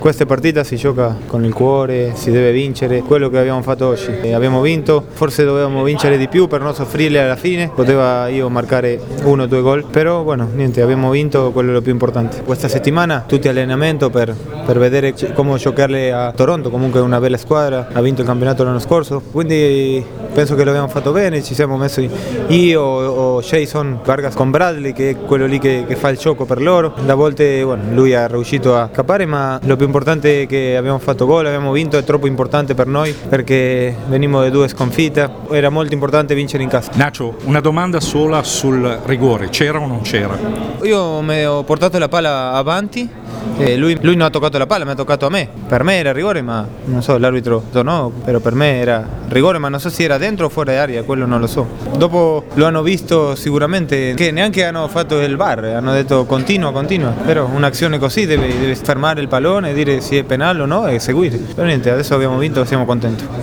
Le interviste del post-partita: